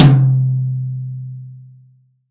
M-TOM12C-1.wav